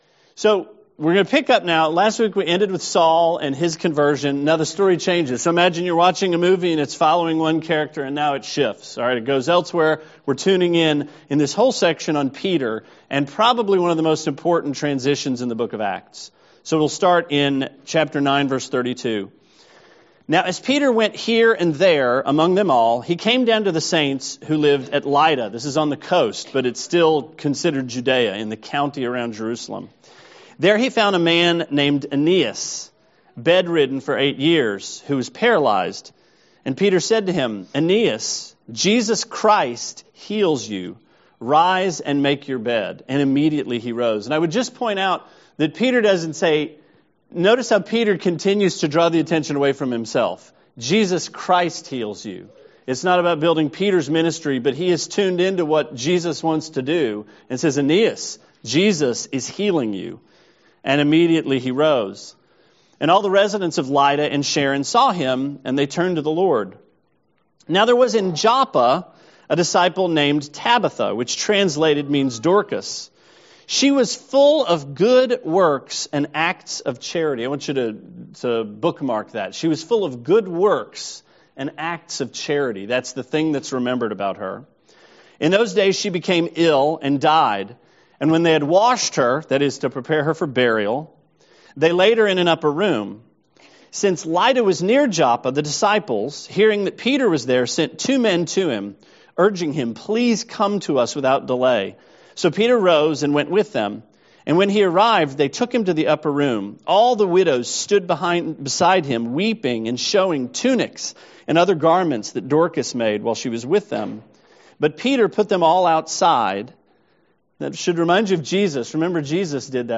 Sermon 5/27: Acts 10: Cornelius